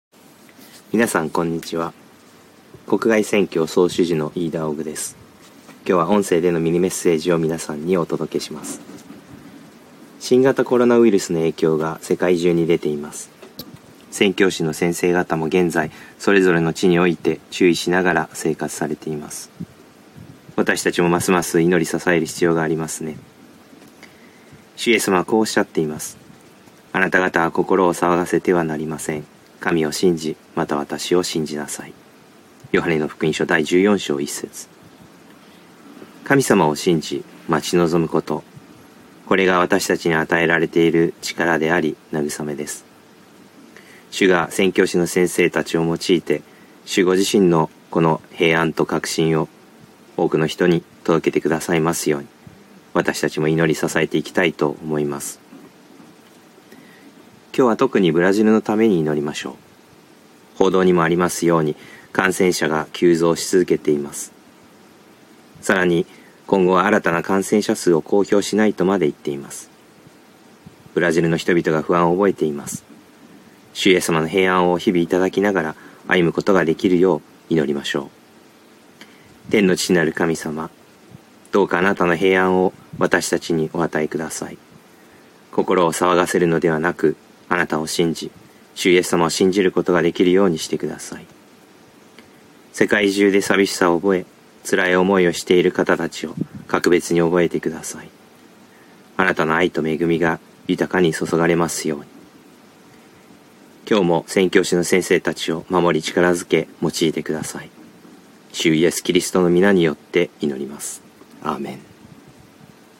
音声ミニメッセージ＃1